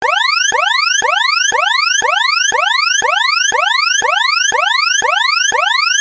alarn_tune.mp3